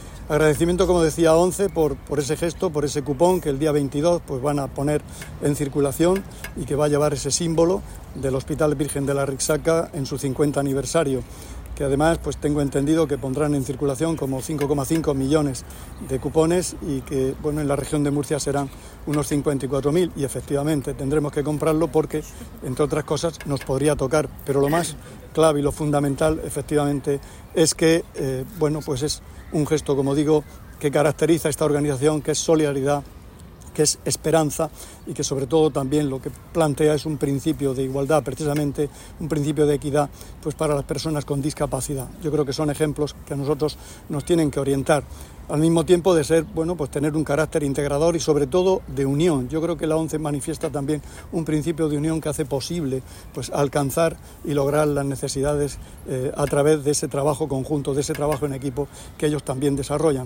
Declaraciones del consejero de Salud, Juan José Pedreño, sobre el cupón de la ONCE con motivo del 50 aniversario del hospital Virgen de la Arrixaca. [mp3]